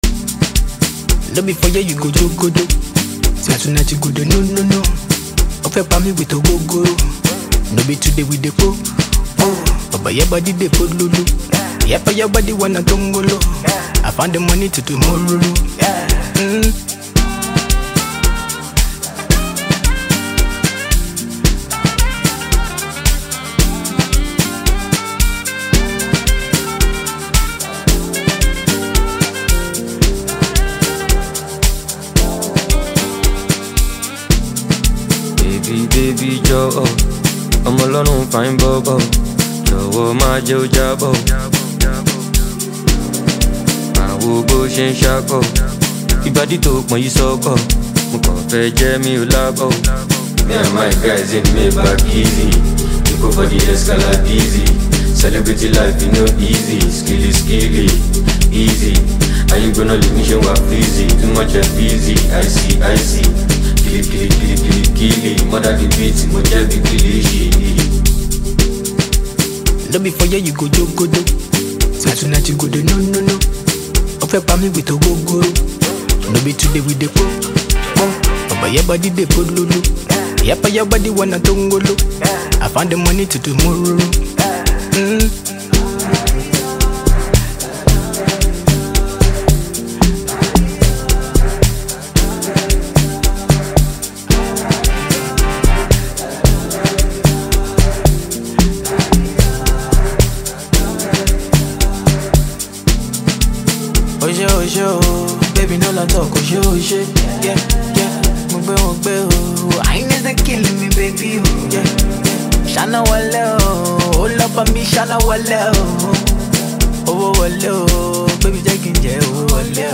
a highly talented Nigerian afrobeat singer-songwriter.